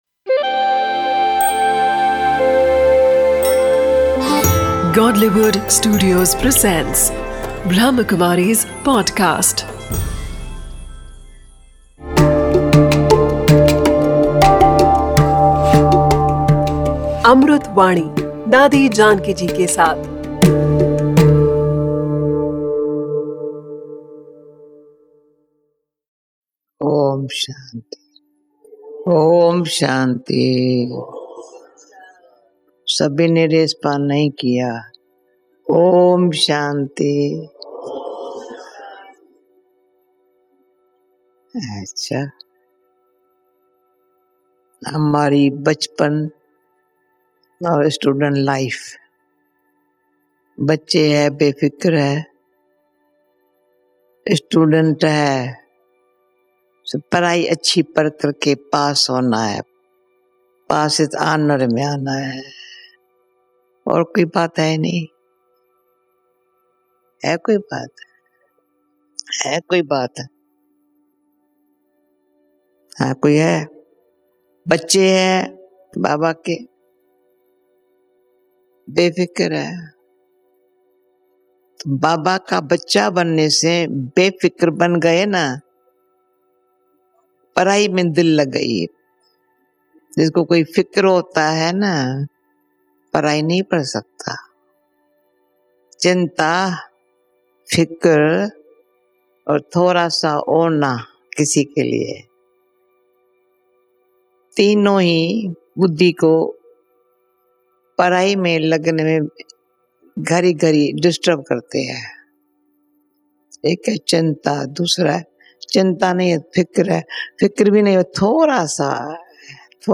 'Amrut Vani' is a collection of invaluable speeches